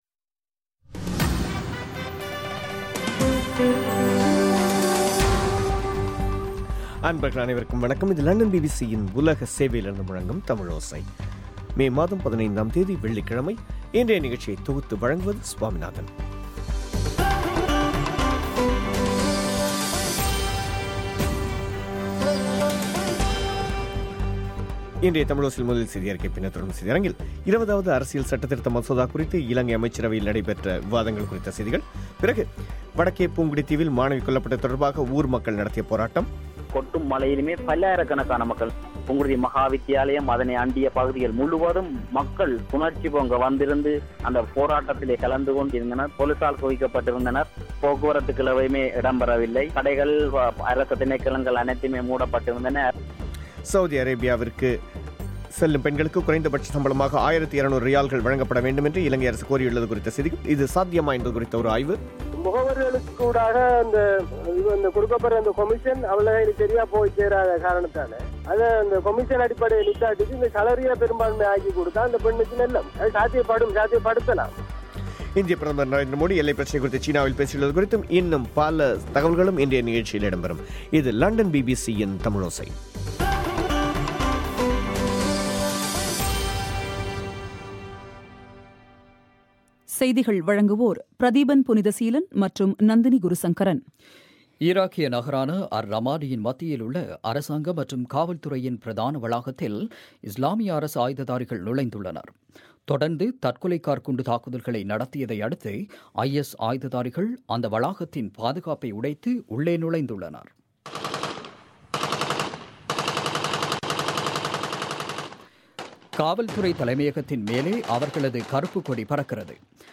முக்கியச் செய்திகள்